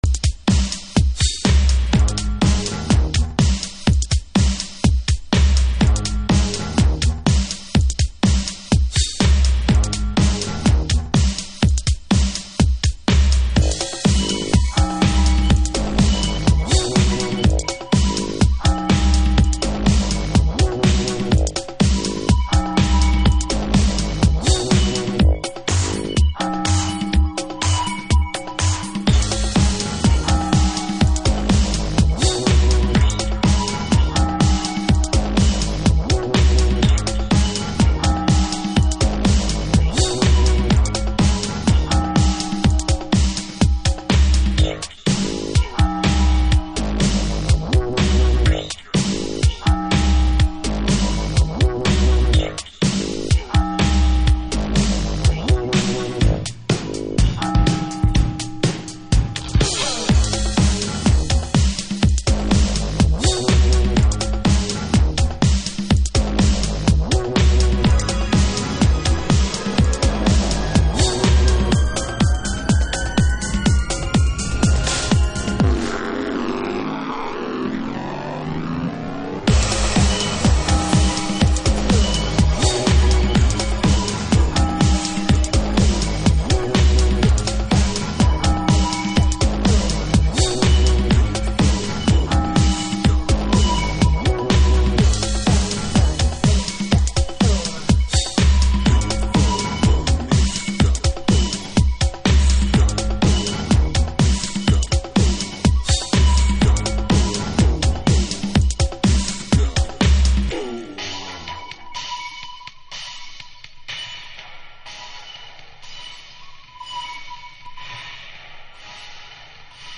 House / Techno
パワフルなトラックメイクですね。